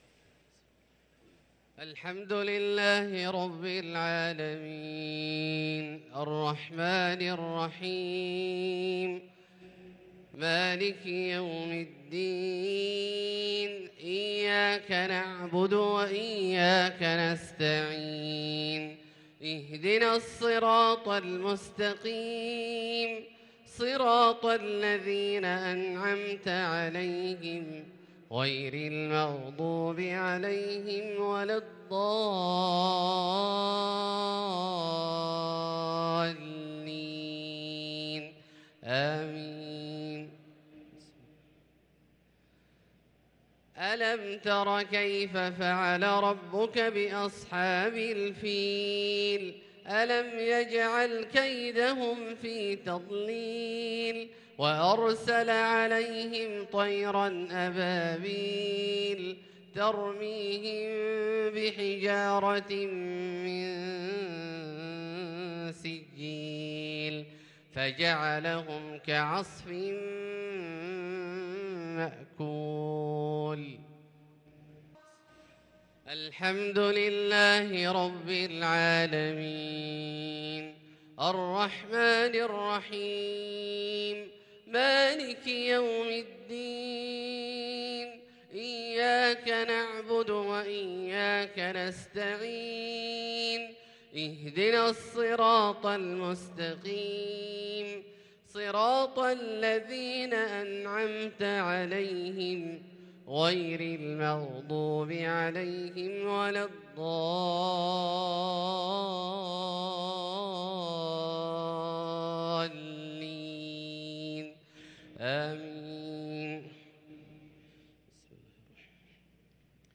صلاة المغرب للقارئ عبدالله الجهني 10 ذو الحجة 1443 هـ
تِلَاوَات الْحَرَمَيْن .